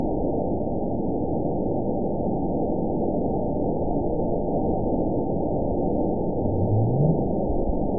event 922714 date 03/17/25 time 22:50:19 GMT (1 month, 2 weeks ago) score 9.53 location TSS-AB04 detected by nrw target species NRW annotations +NRW Spectrogram: Frequency (kHz) vs. Time (s) audio not available .wav